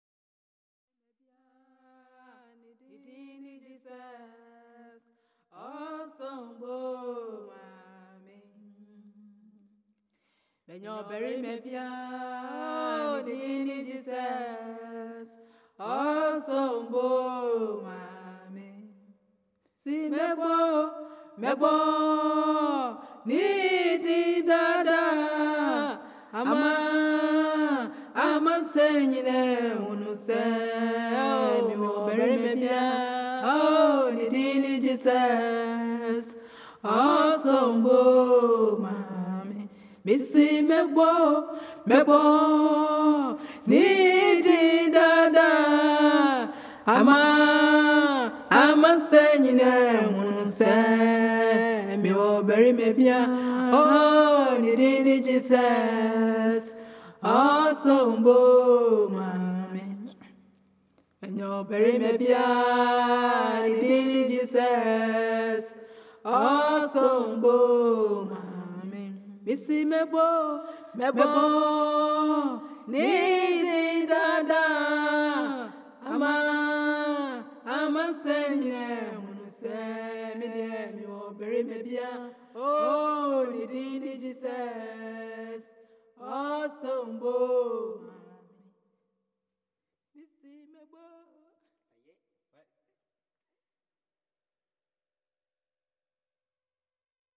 Un gospel ghanéen interprété par l’amie de mon père. Un enregistrement pour lequel je ne suis intervenu qu’en tant qu’ingénieur du son.